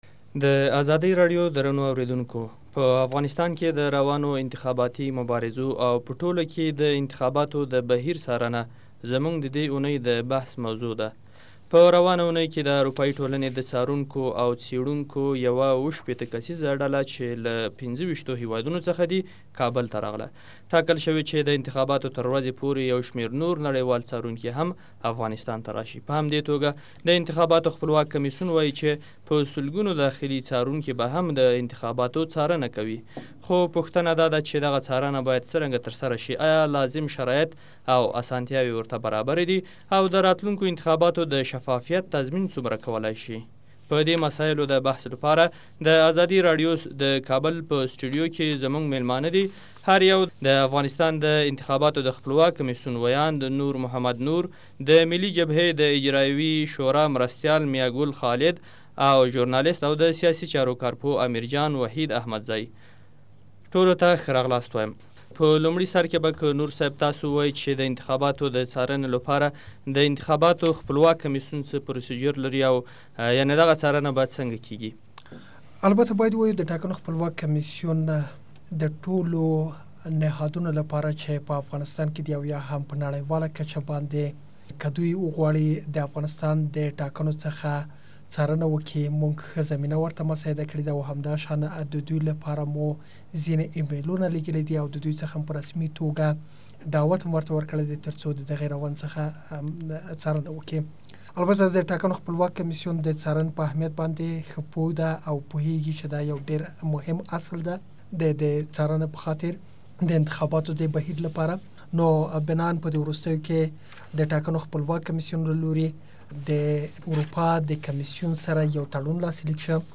د انتخاباتو بهيربه څوک څاري؟ بحث واورﺉ